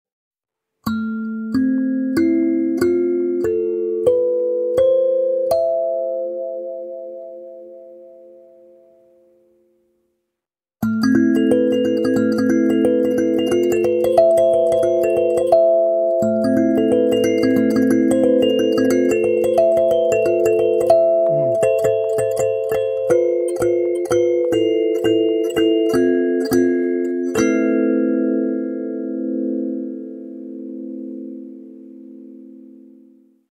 The resonance body creates a full and round, vibrating sound. The light Sansula Basic is covered with a thin, sound-optimized membrane.
Mood: A-Minor Pentatonic
This tuning – a-minor with additional tines pitched at B and F – allows wonderful melodies to be produced almost of their own accord, simply by plucking the tines with the thumbs.
(A4, C5, C4, A4, A3, F4, E4, E5, H/B4)
sansula-basic-a-moll-sound-sample.mp3